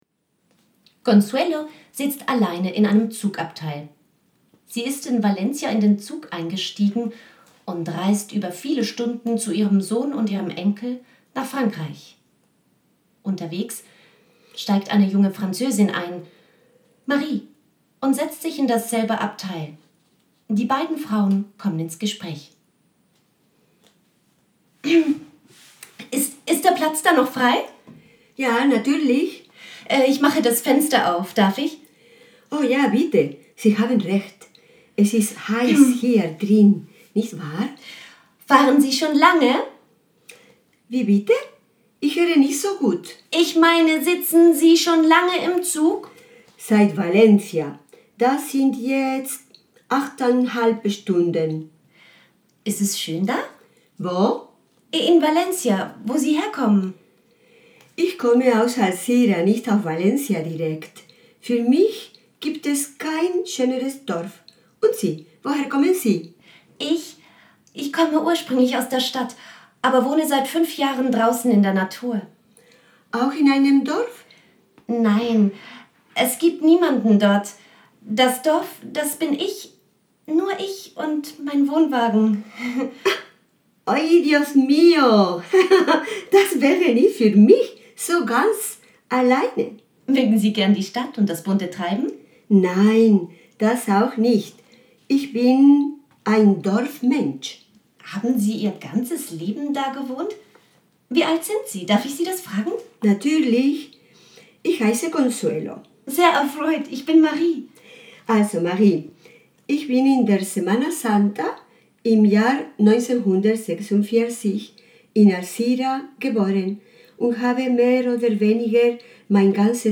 Die Hörbeiträge aus dem Tram